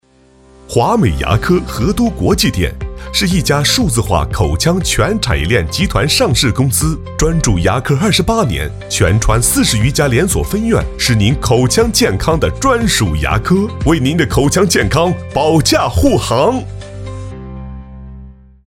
C男134号 | 声腾文化传媒
【广告】华美牙科.mp3